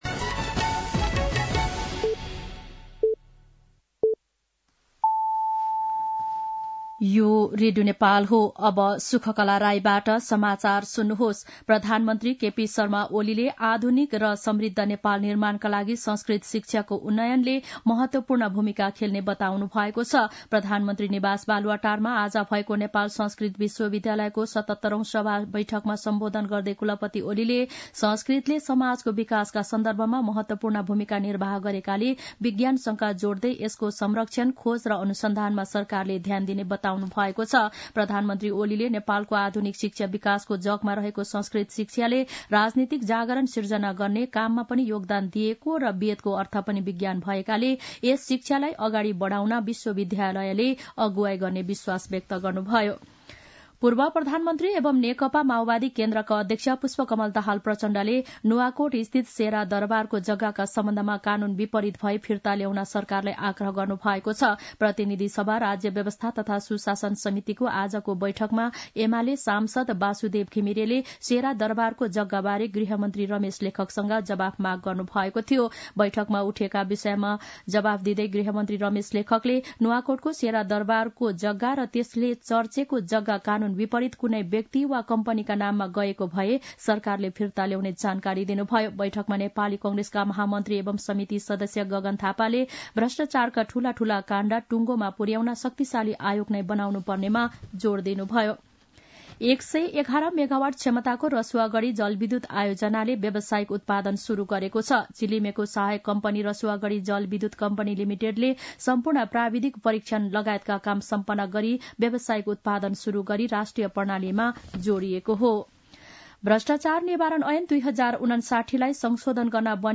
साँझ ५ बजेको नेपाली समाचार : १९ पुष , २०८१
5-pm-nepali-news-9-18.mp3